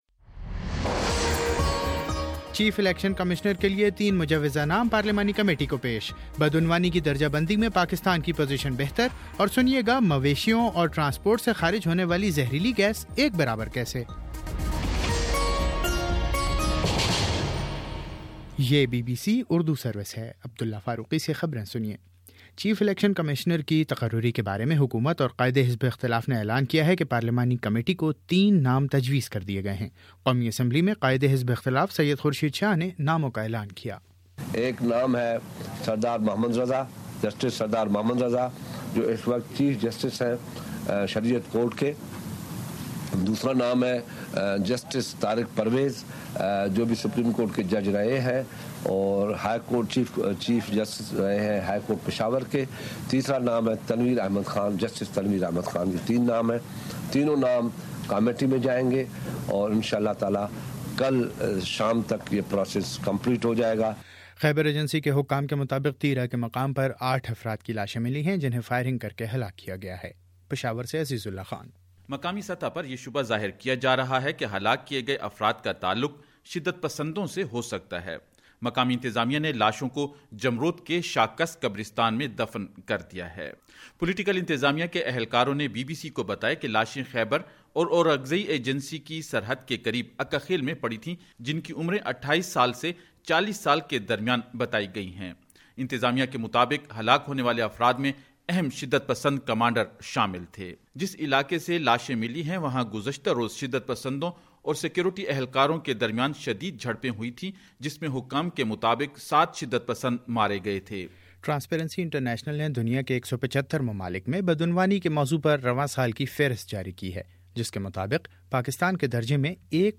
دسمبر03: شام سات بجے کا نیوز بُلیٹن